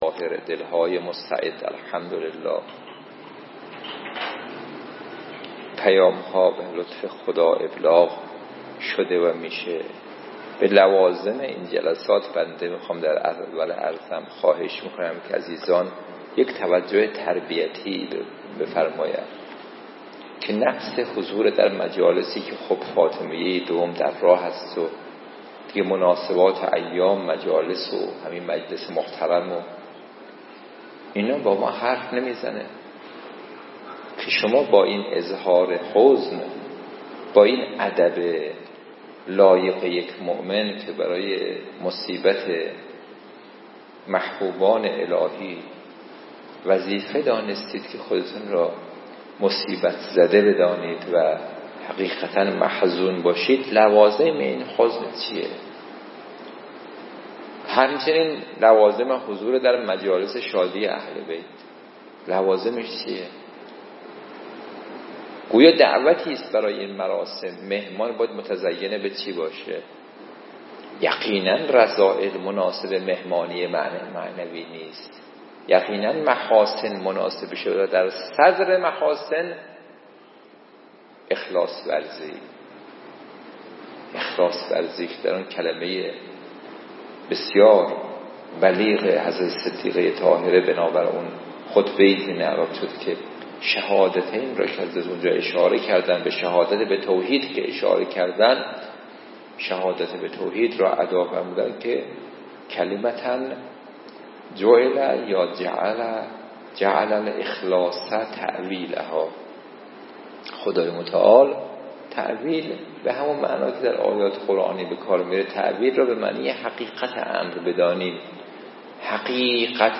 درس الاخلاق